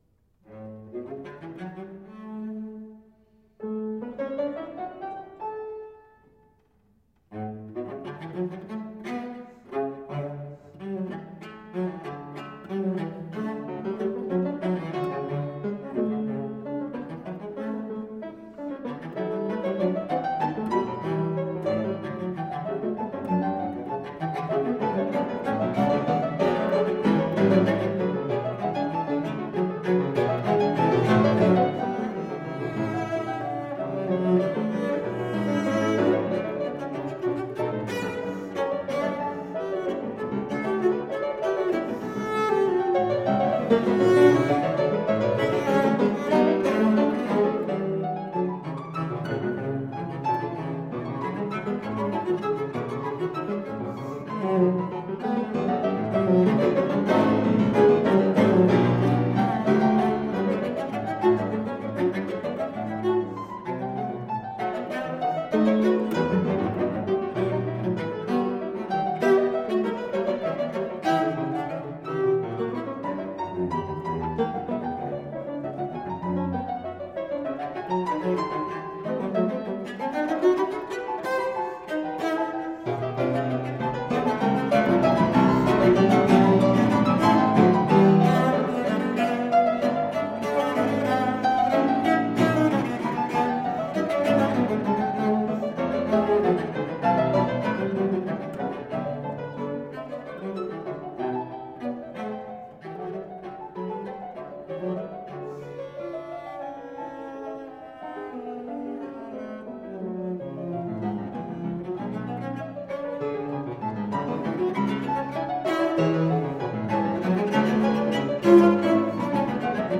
Cello, Classical Piano